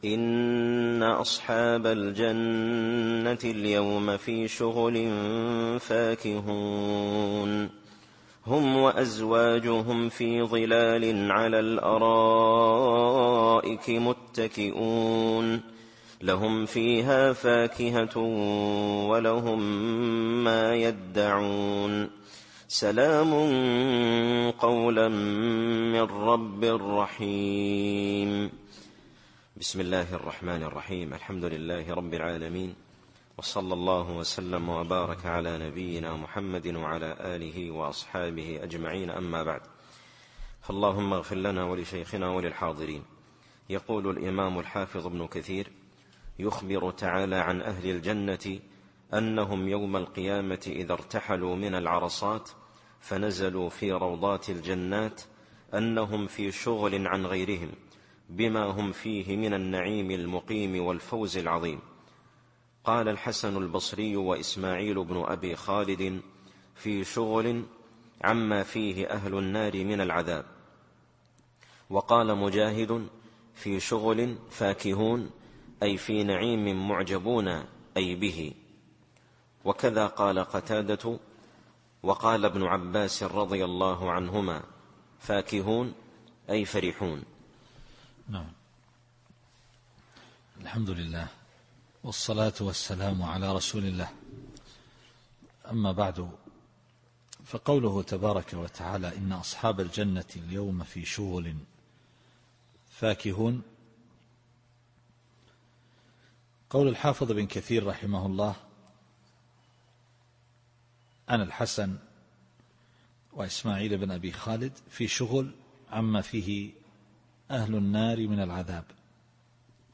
التفسير الصوتي [يس / 55]